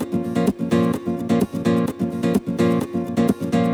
VEH3 Nylon Guitar Kit 2 - 4 A# min.wav